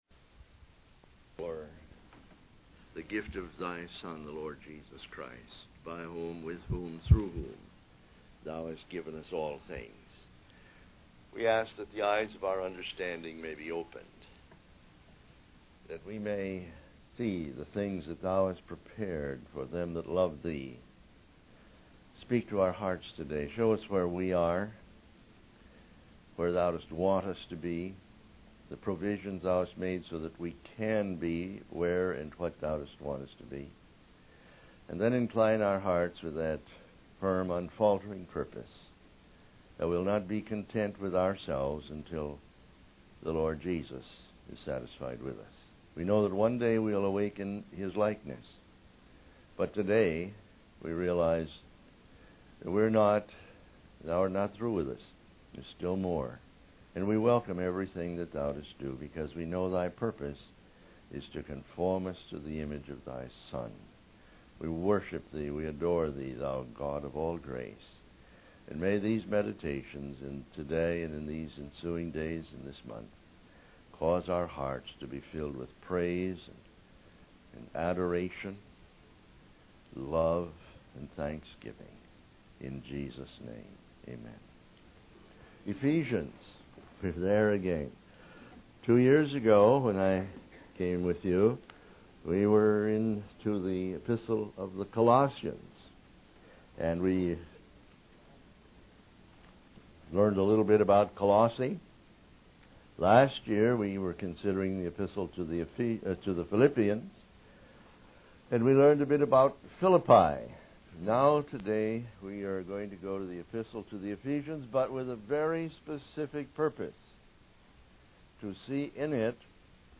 The preacher also explains that although we may know God as our loving Father, we must not forget that he is also the sovereign ruler of the universe. The sermon further discusses how God's plan of salvation is intricately connected to his sovereign choice of recipients for his grace.